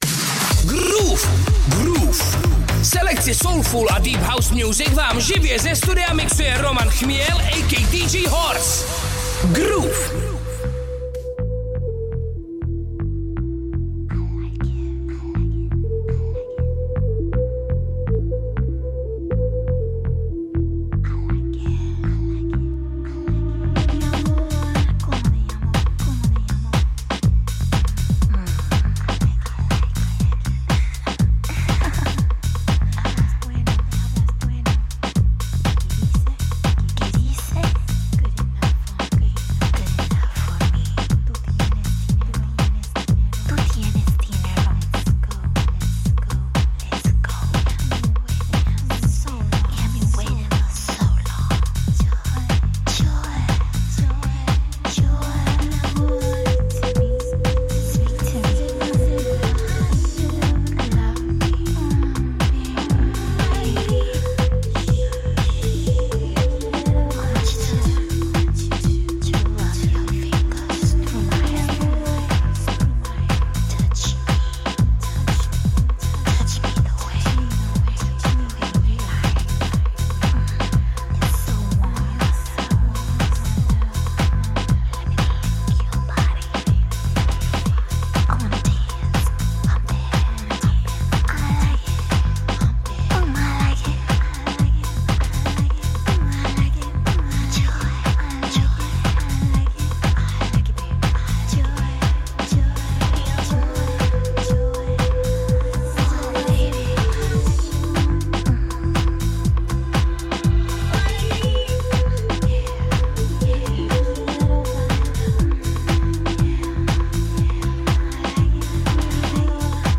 live, vinyl set